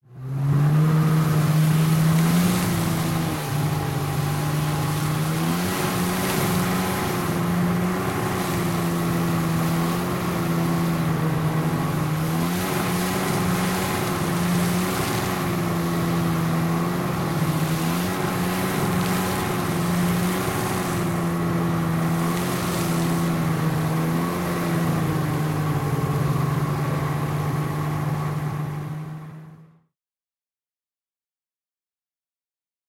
Звуки гидроцикла: Гул мотора при езде на гидроцикле